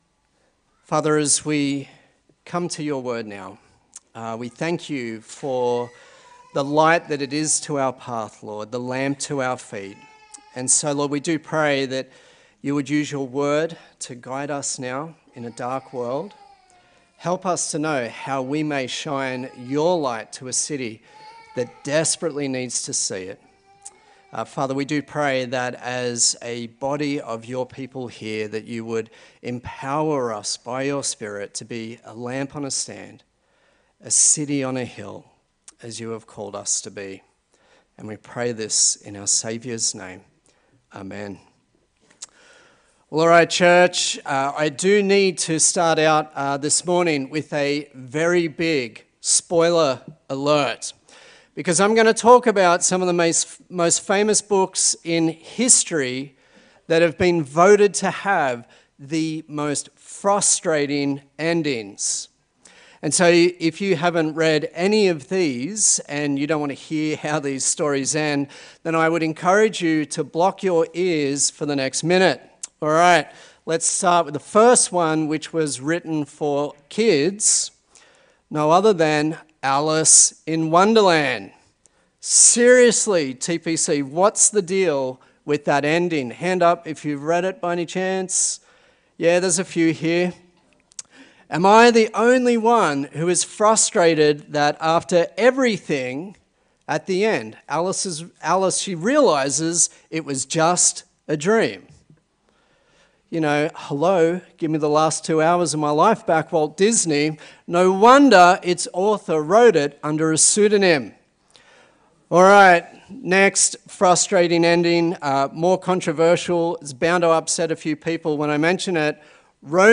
Acts Passage: Acts 28:11-31 Service Type: Sunday Service